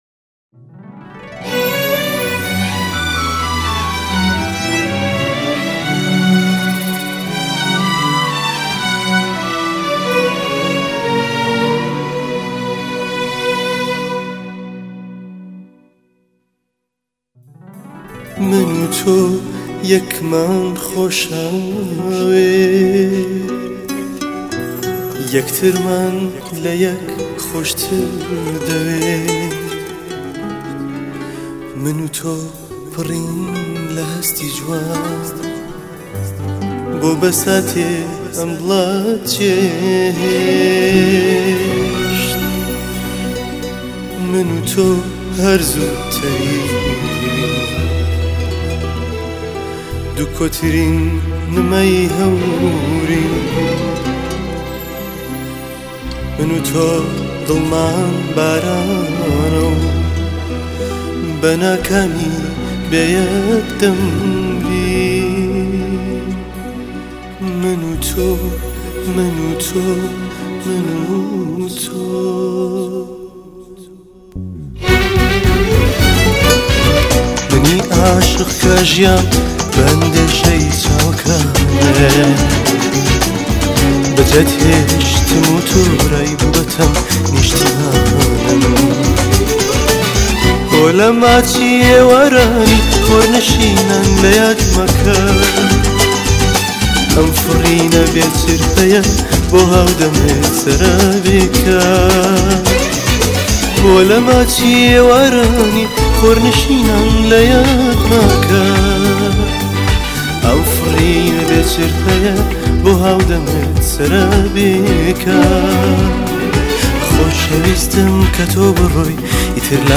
کەمان
چەلۆ
کلارنێت
گیتار
کیبۆرد